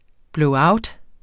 Udtale [ blɔwˈɑwd ]